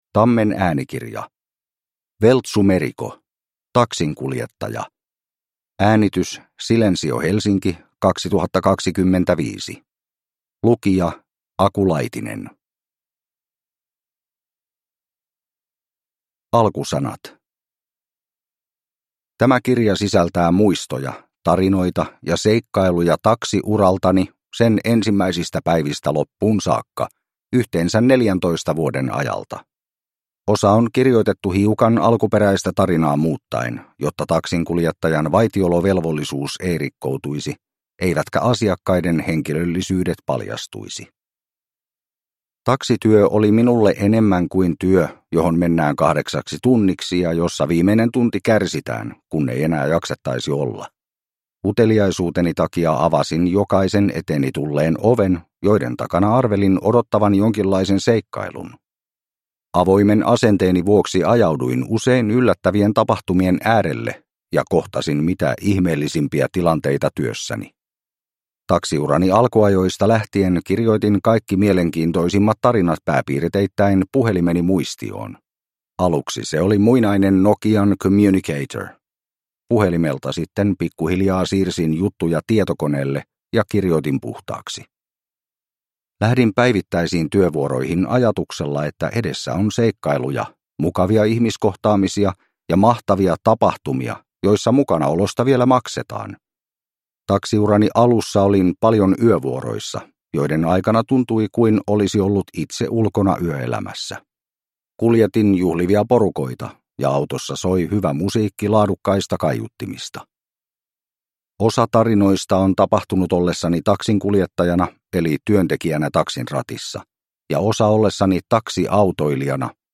Taksinkuljettaja – Ljudbok